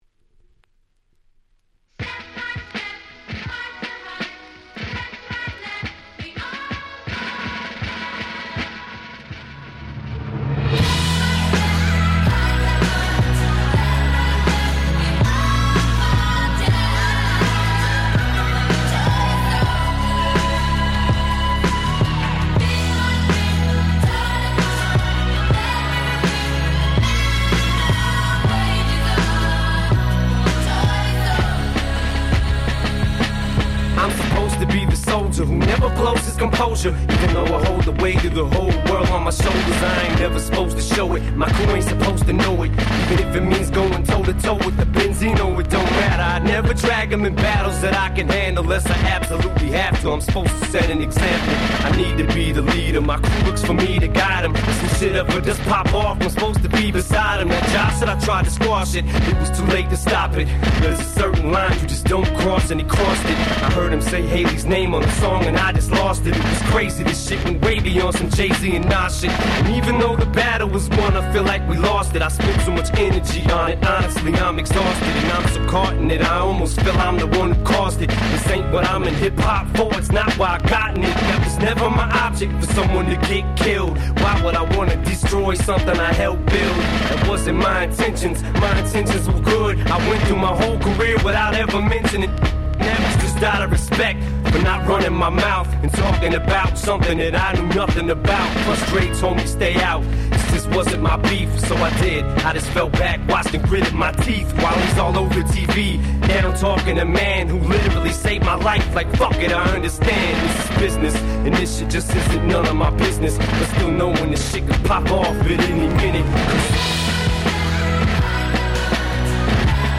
04' Super Hit Hip Hop !!